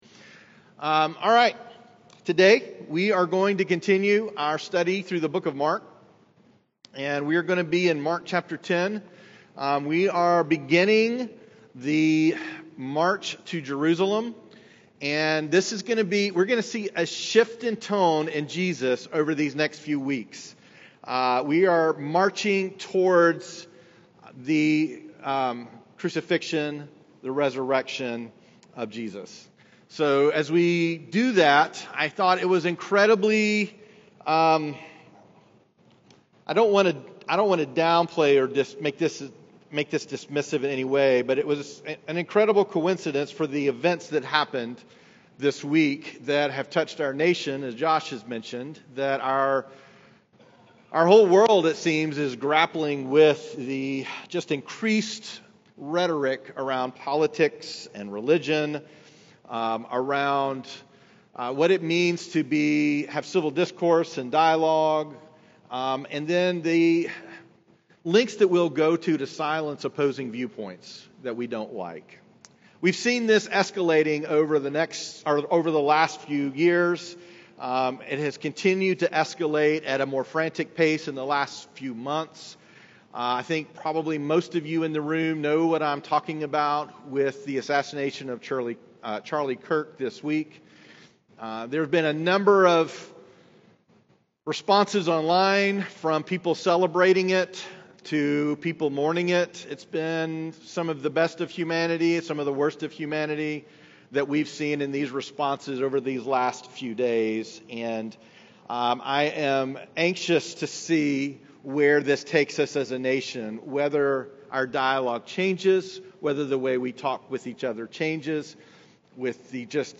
Latest Sermon - Journey Church